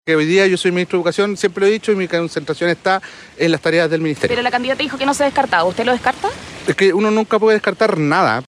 Ante esto, el ministro de Educación, Nicolás Cataldo, en un breve diálogo con la prensa en La Moneda, abordó la posibilidad de salir del gabinete para sumarse al equipo de Jara, afirmando que “uno no nunca puede descartar nada”.